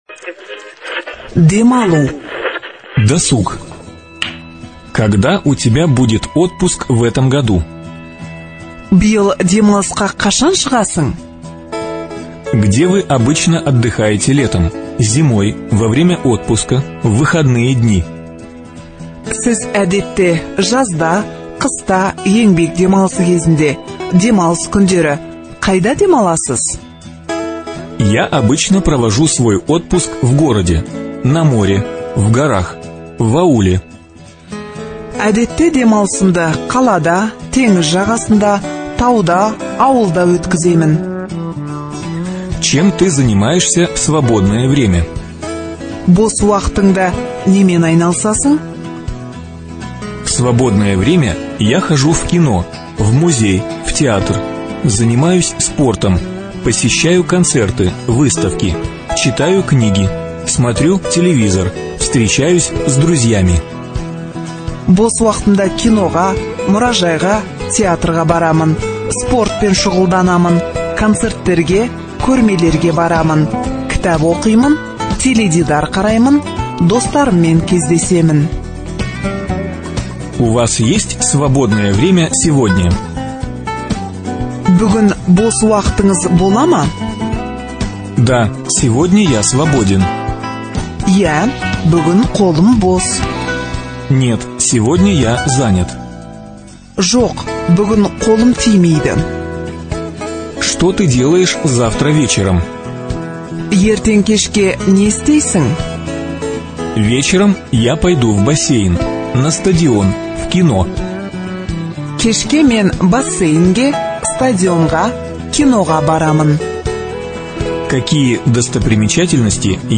Говорим (аудио разговорники)